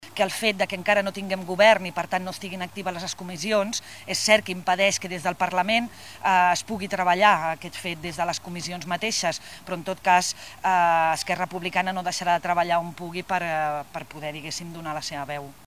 Per la seva banda, la diputada al Parlament de Catalunya Mònica Palacín expressava que és necessari formar govern per “treballar des de la comissió de medi ambient”.